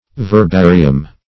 Search Result for " verbarium" : The Collaborative International Dictionary of English v.0.48: Verbarium \Ver*ba"ri*um\, n. [NL., fr. L. verbum word.]